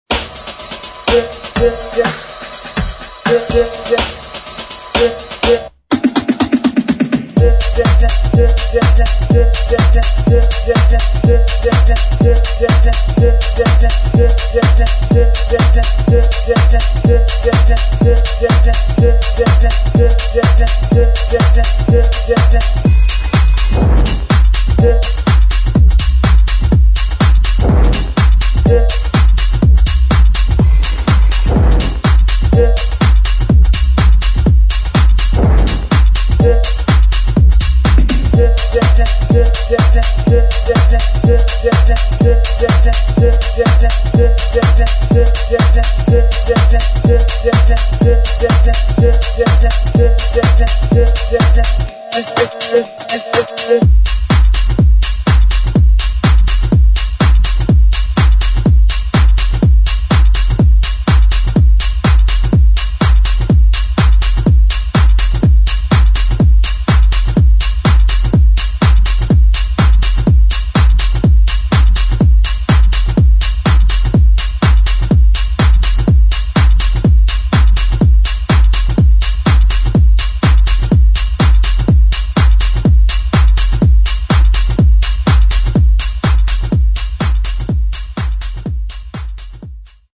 [ DEEP HOUSE / TECH HOUSE ]